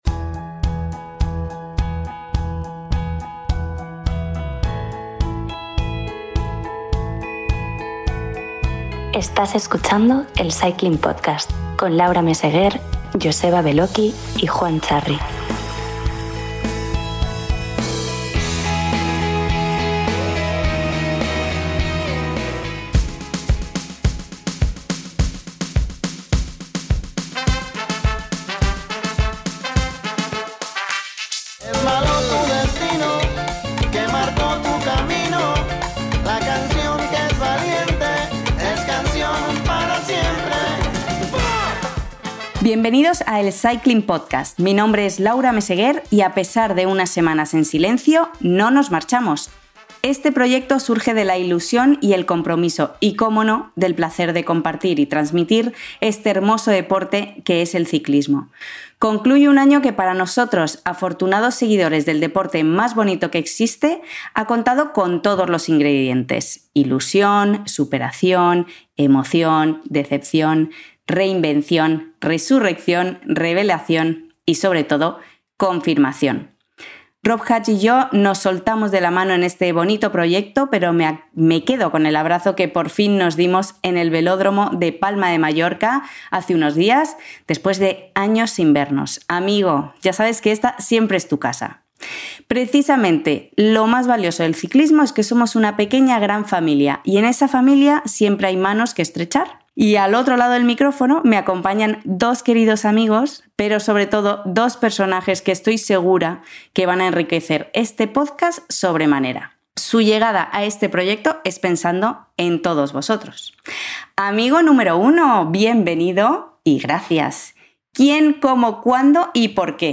Regresa El Cycling Podcast con dos nuevas voces